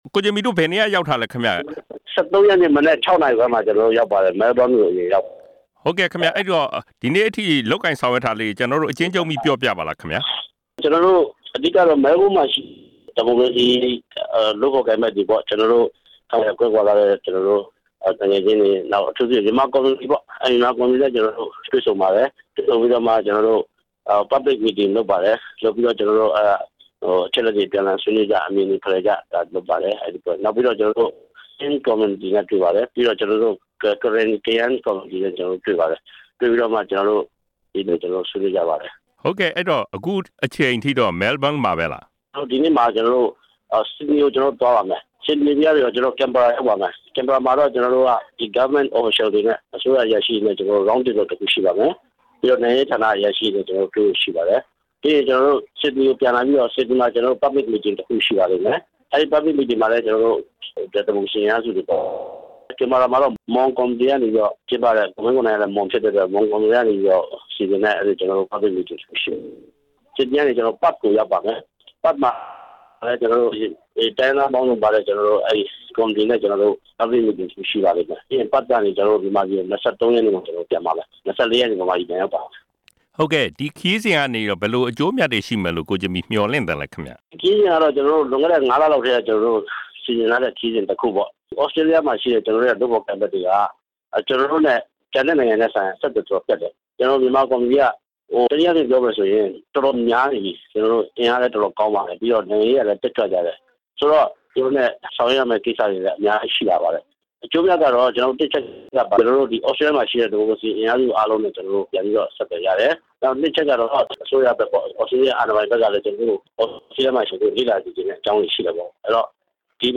၈၈ မျိုးဆက်က ကိုဂျင်မီနဲ့ ဆက်သွယ်မေးမြန်းချက်